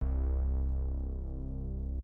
Moog Bass.wav